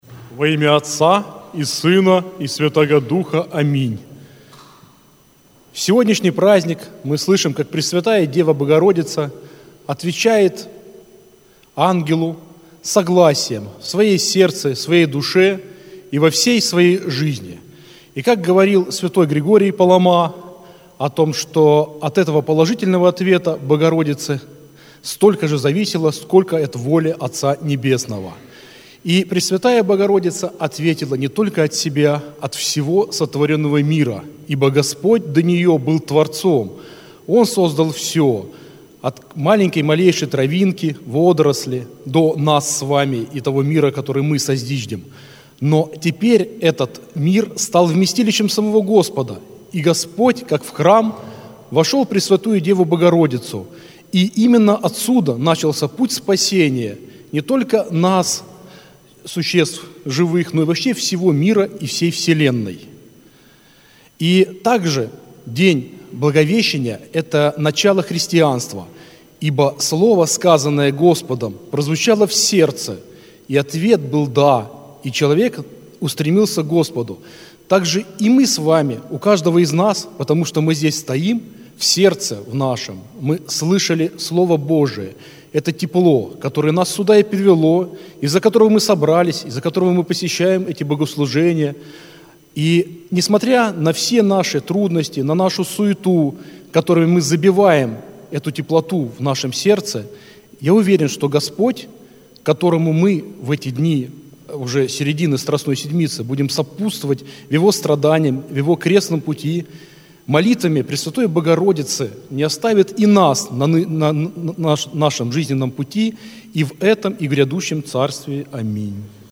Аудиозапись проповеди